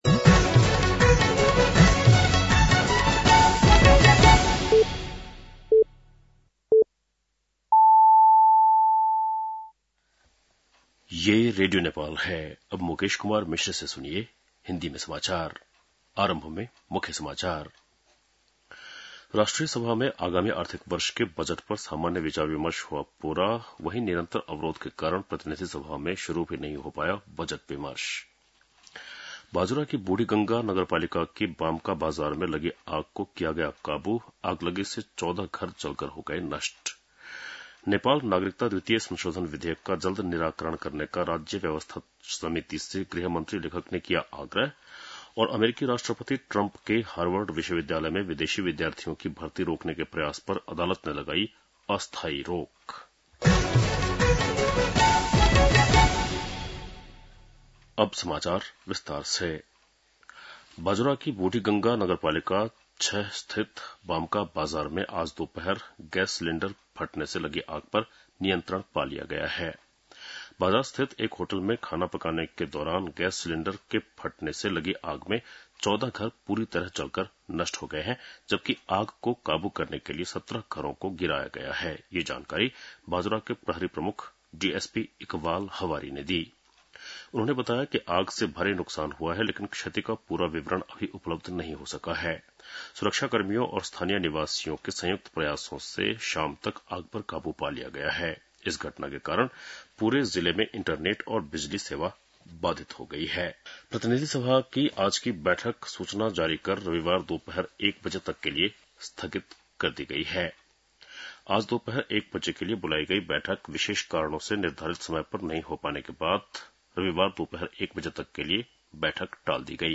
बेलुकी १० बजेको हिन्दी समाचार : २३ जेठ , २०८२
10-pm-hindii-news-2-23.mp3